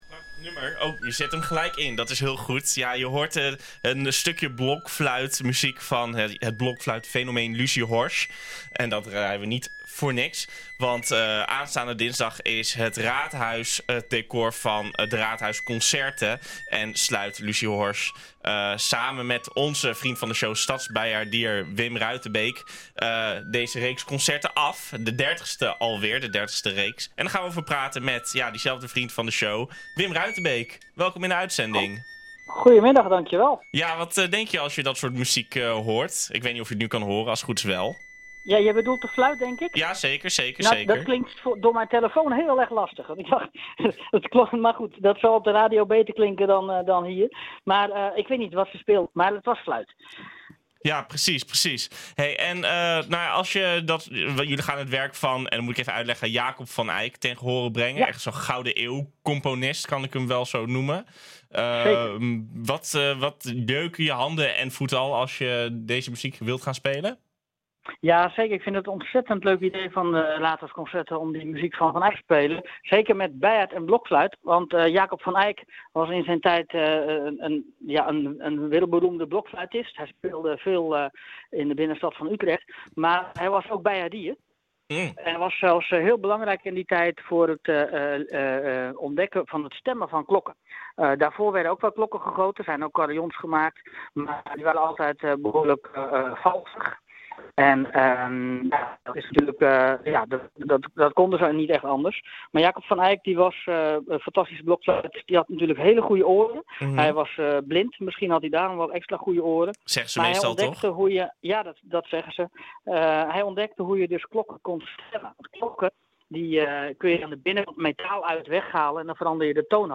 We praten erover met diezelfde vriend van de show